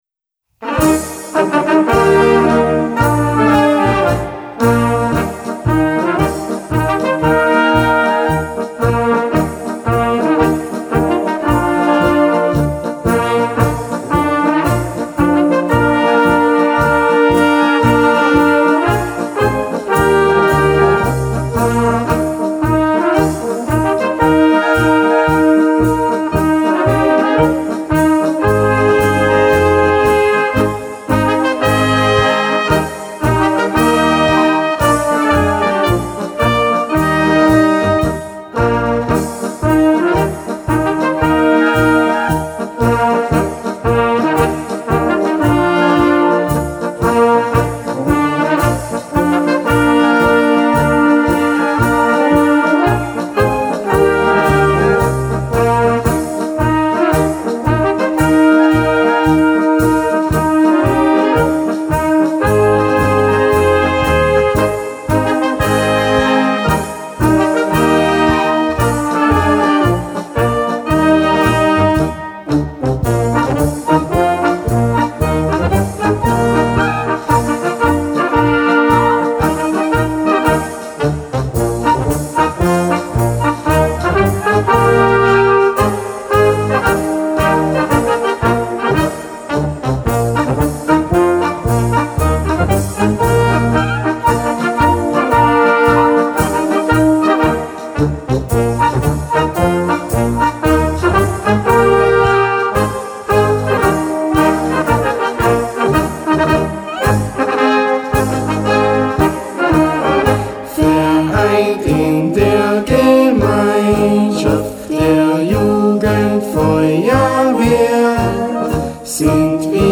Blasorchester
Schwierigkeitsgrad: 2 (mittel)